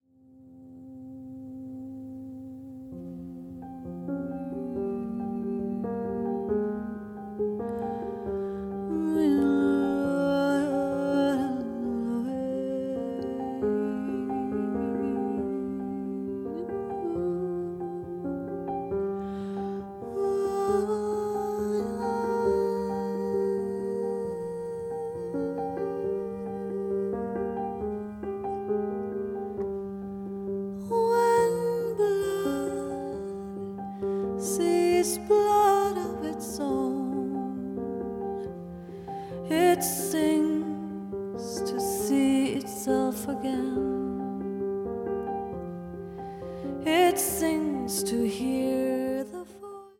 彼らしい知的でハイセンスなサウンドが展開する”大人な”アルバムです。
voice and live electronics
acoustic bass, electric bass
piano, Fender Rhodes, keyboards
drums and percussions